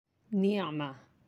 (ni’mah)